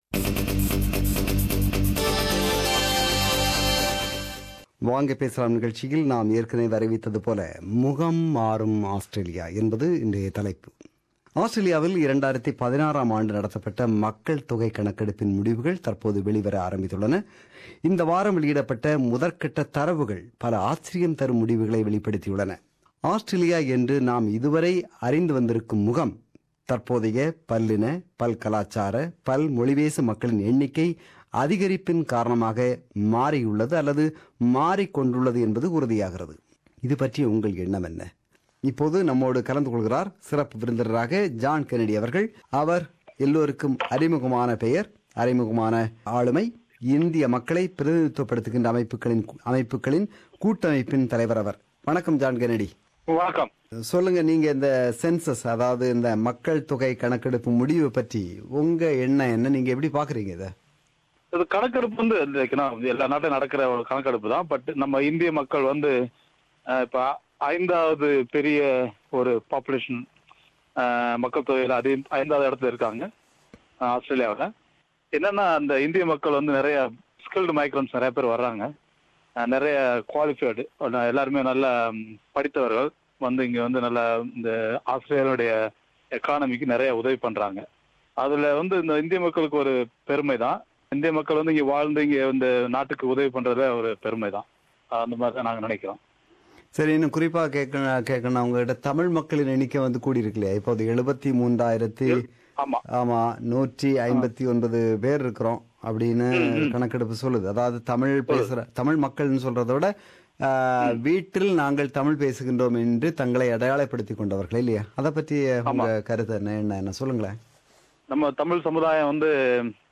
This is the compilation of opinions expressed by our listeners who participated in Talkback (Vanga Pesalam) program on 30 June 2017.